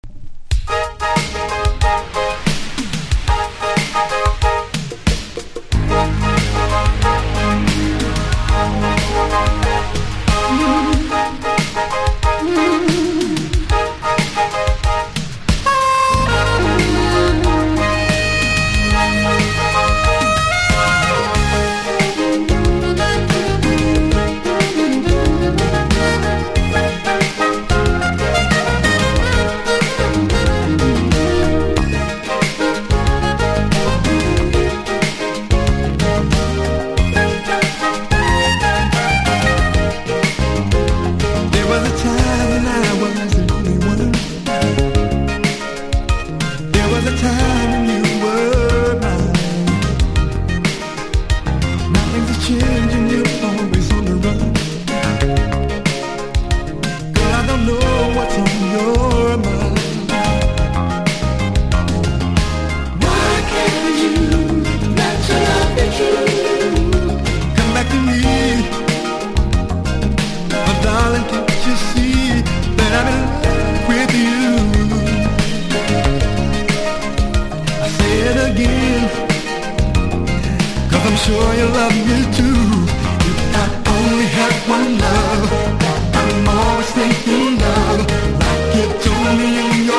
清涼感あるフュージョン作品。
FUSION / JAZZ ROCK